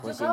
[puçin] noun worm